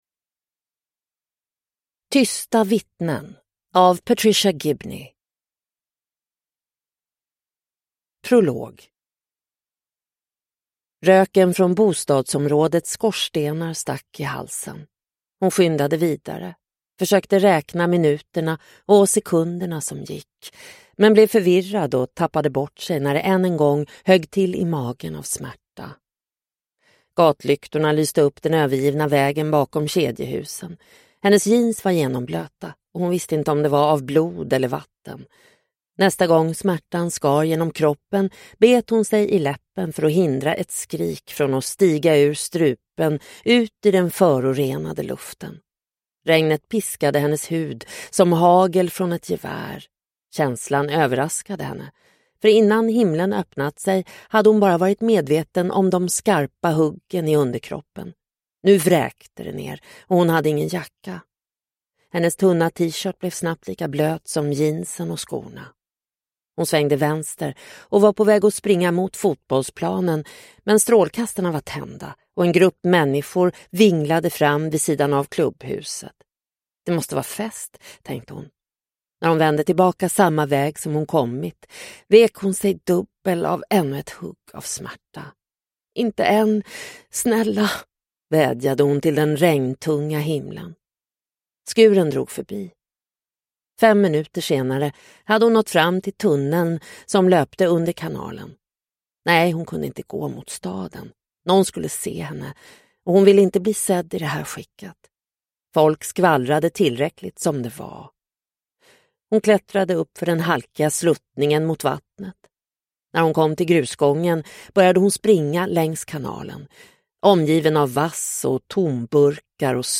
Tysta vittnen – Ljudbok – Laddas ner